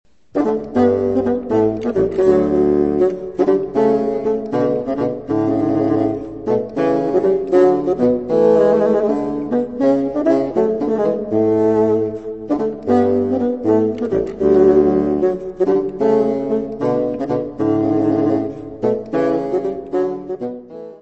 fagote
Music Category/Genre:  Classical Music
Gigue.